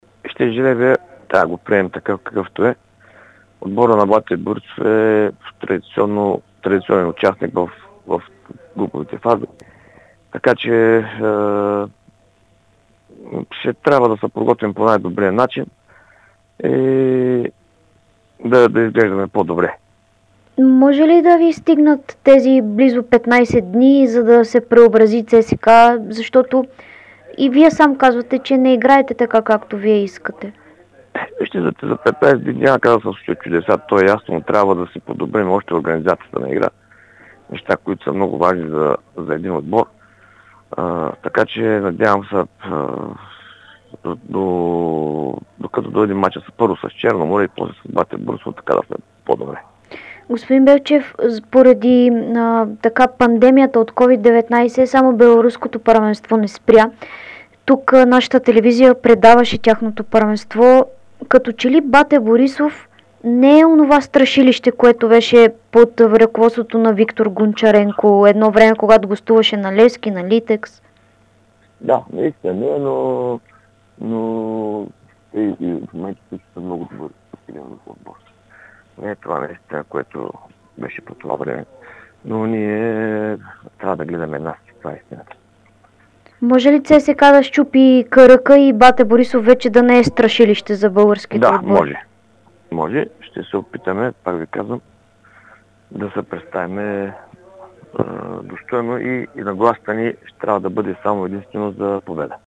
Старши треньорът на ЦСКА Стамен Белчев заяви пред Дарик и dsport, че ЦСКА може да счупи каръка на българските тимове и да победи БАТЕ Борисов във втория квалификационен кръг на Лига Европа.